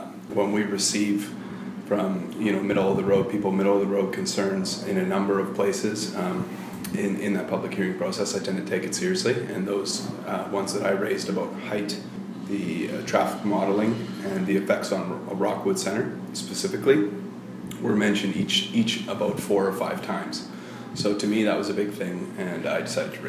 Councilor Noel Muller says some issues were brought up more than once.